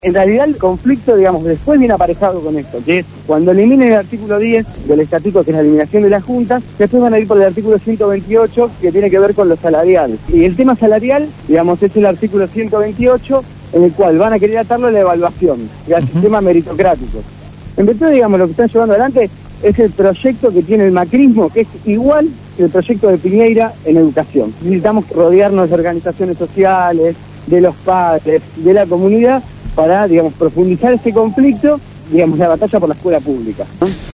en el programa Desde el Barrio por Radio Gráfica FM 89.3